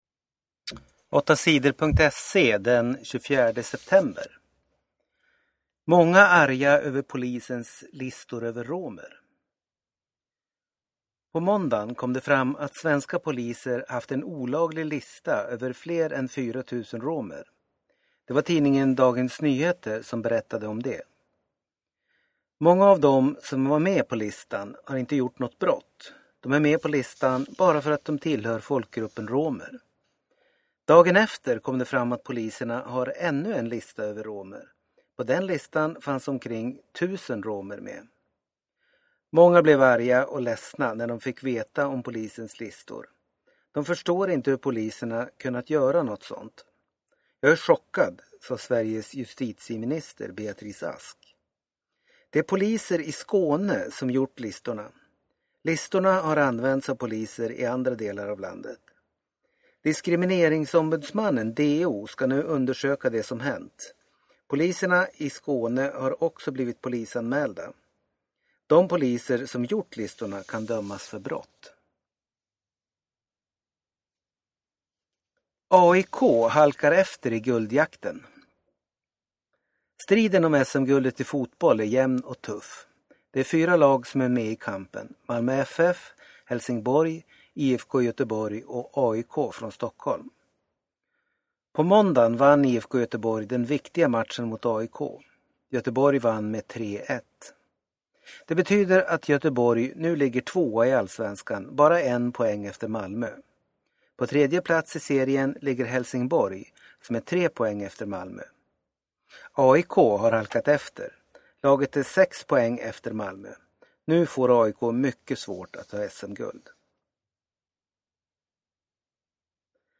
Inlästa nyheter den 24 september